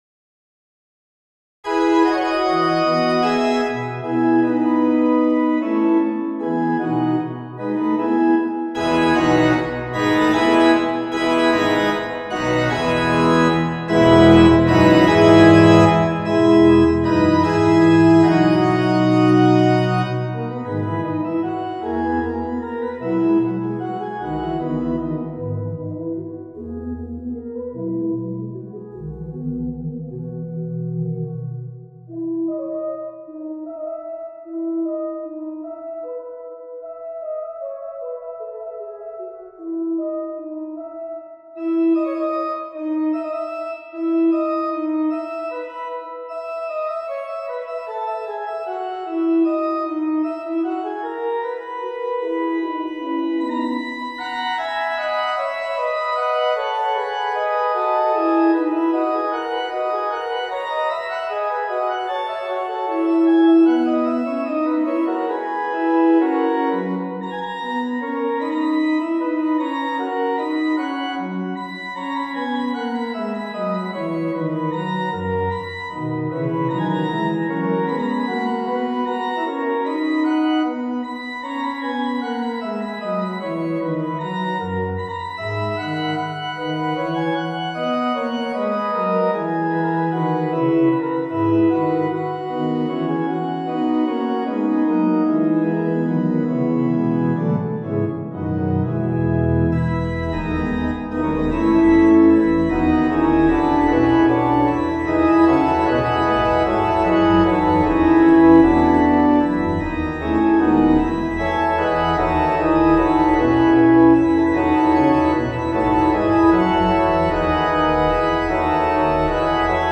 for organ
A subject is made from the upward leap of a seventh and then downward leap of a ninth, to "circle" the "do." That opening gesture, twice repeated, then is ended with a simple downward gesture and repetition of the opening.
Some freedom via rubato is suggested, in an improvisatorial manner.